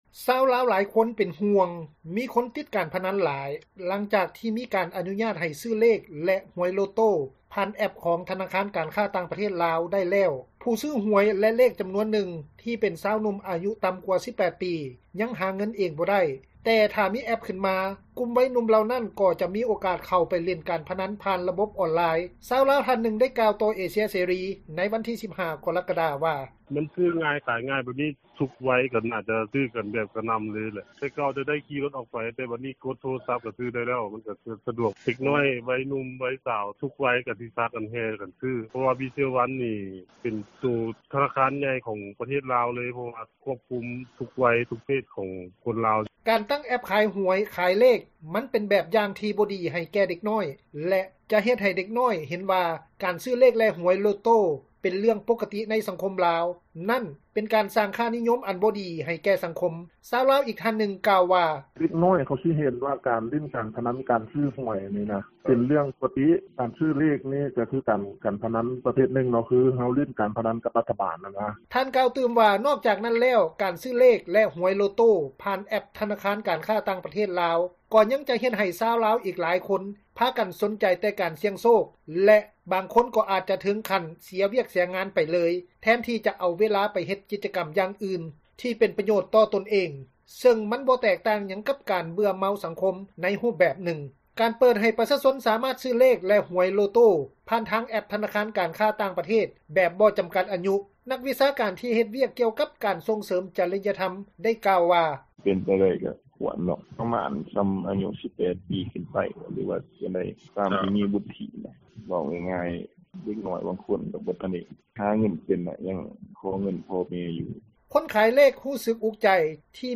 ຊາວລາວທ່ານນຶ່ງ ໄດ້ກ່າວຕໍ່ເອເຊັຽເສຣີ ໃນວັນທີ 15 ກໍຣະກະດາ ວ່າ:
ແມ່ຄ້າ ຂາຍເລກຢູ່ແຄມທາງ ນາງນຶ່ງ ກ່າວວ່າ: